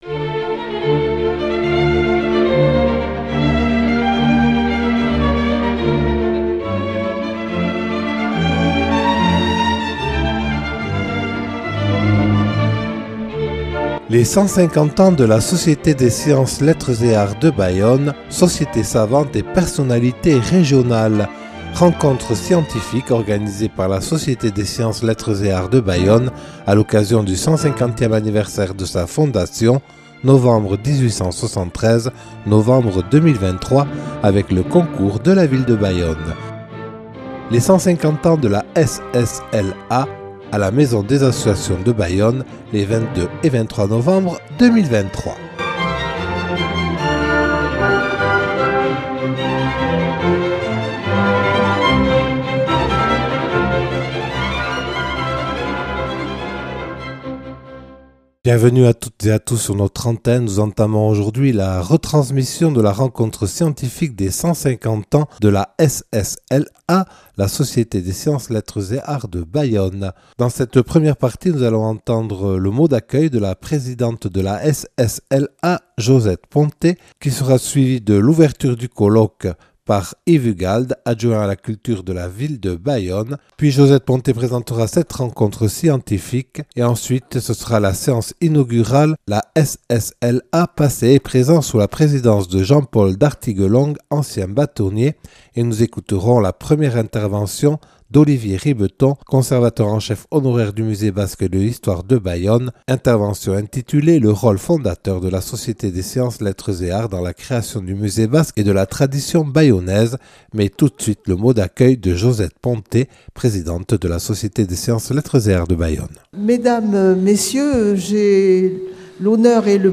Ouverture du colloque des 150ans de la SSLA de Bayonne